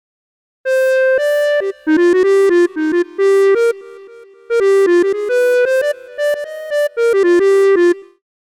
Rideのインストゥルメントモード
DynAssist_Inst_Off.mp3